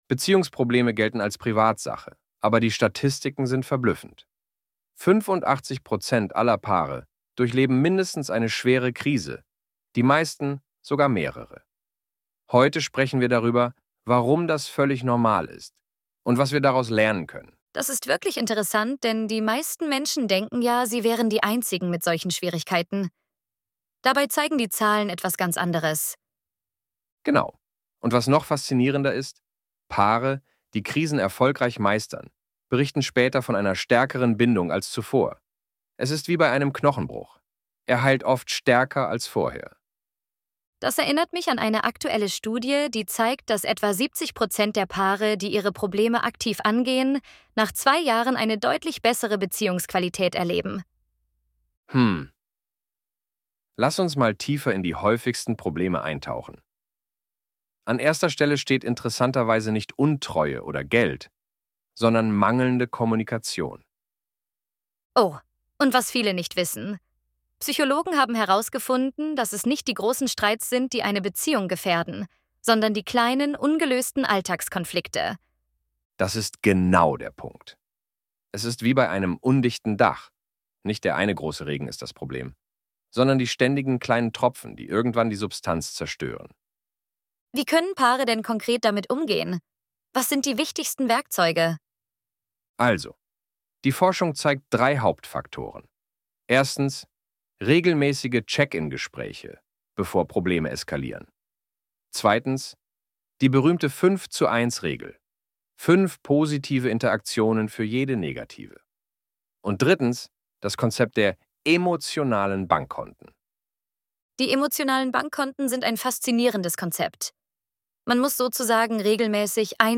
ElevenLabs_Krisen_als_Chance_Staerkung_durch_Beziehungsprobleme.mp3